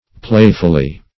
Play"ful*ly, adv.